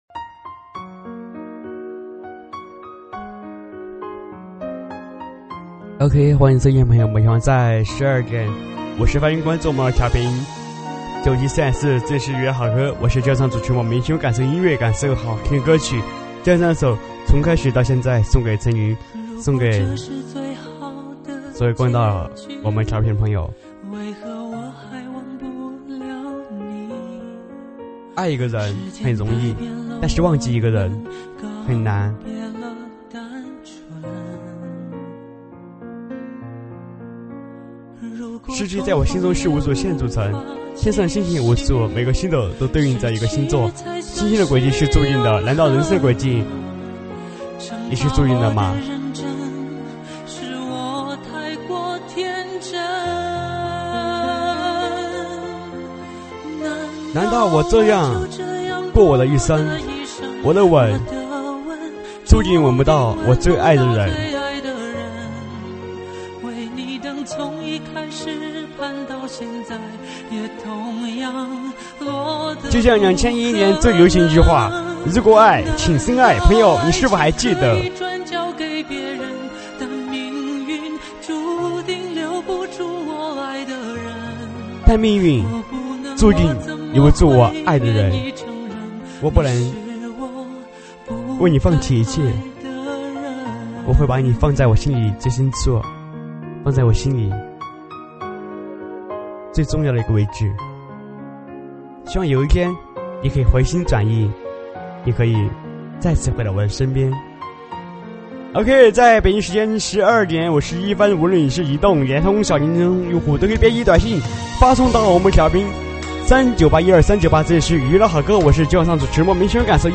舞曲类别：喊麦现场